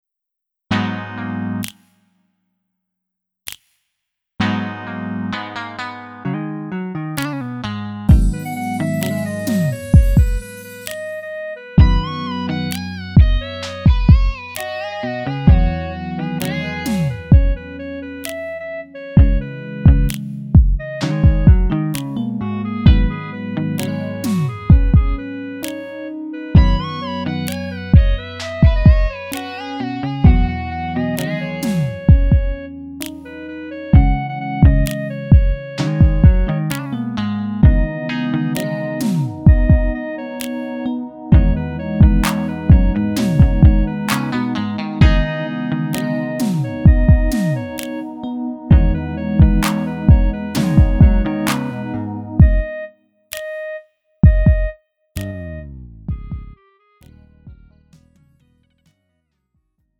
음정 -1키 4:01
장르 가요 구분 Lite MR